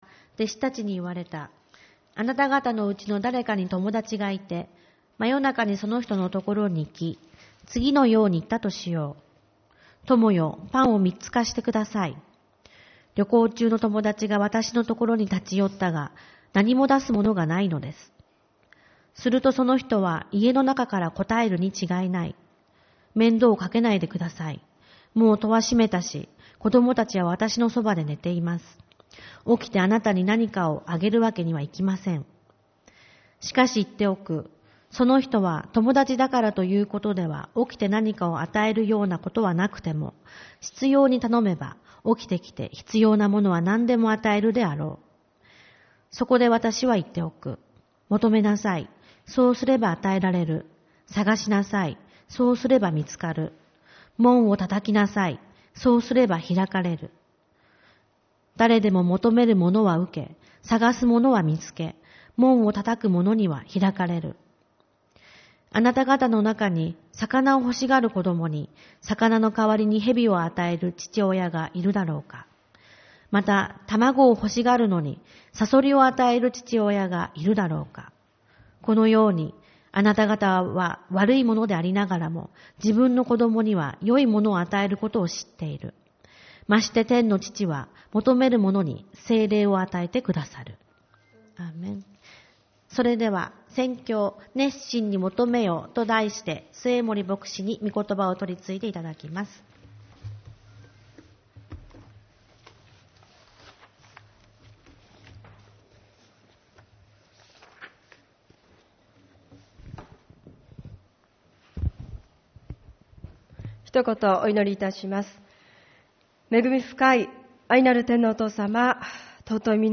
元旦・新年礼拝 「熱心に求め続けよ」 ルカによる福音書11:5-13